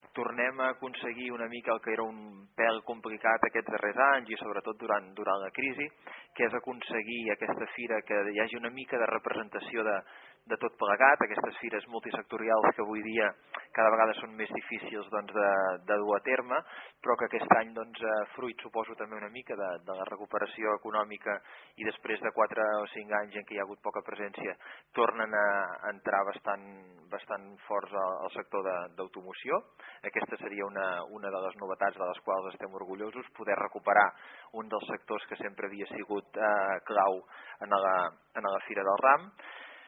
La recuperació de la crisi econòmica estarà molt present en aquesta edició. Són declaracions de Marçal Vilageliu, regidor de Promoció Econòmica de Tordera.
VILAGELIU-1.mp3